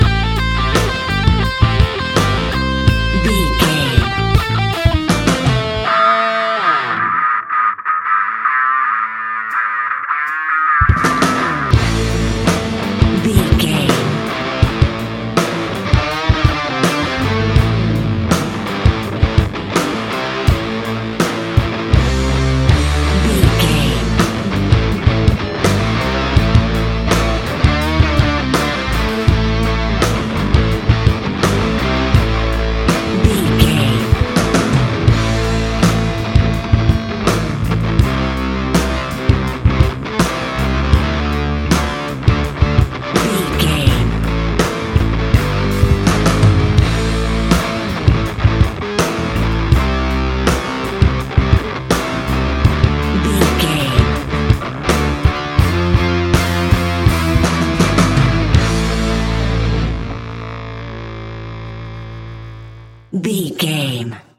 Ionian/Major
A♭
hard rock
heavy rock
distortion
instrumentals